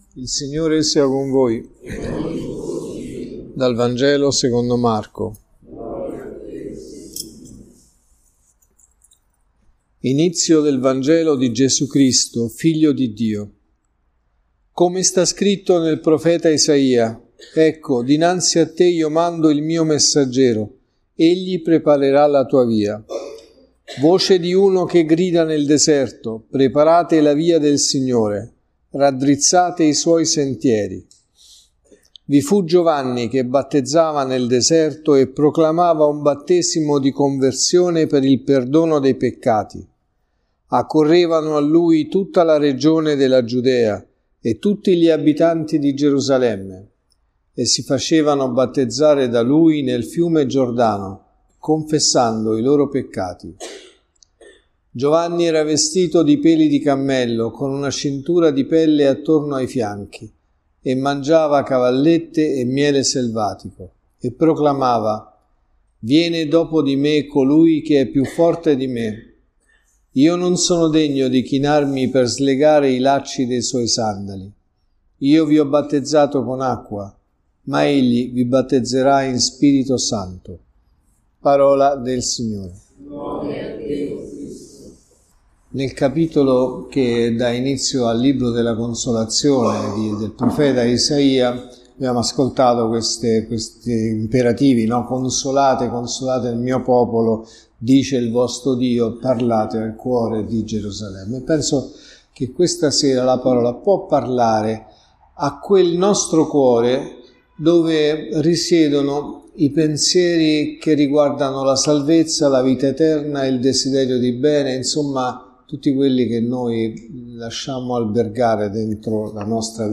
Raddrizzate le vie del Signore.(Messa del mattino e della sera)
Omelie